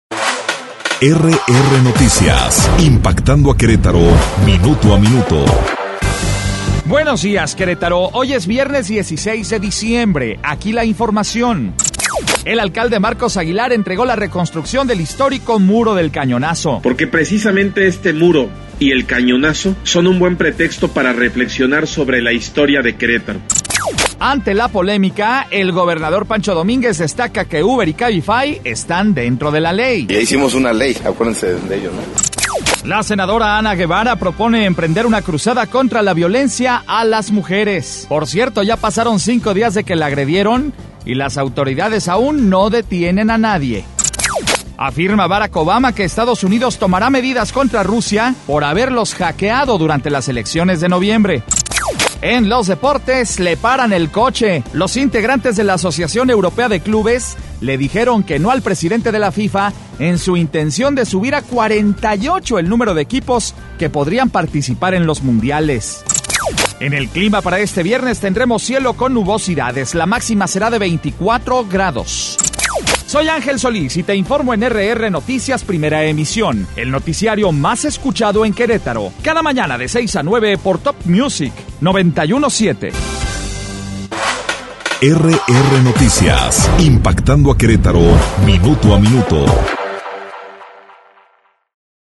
Resumen Informativo 16 de diciembre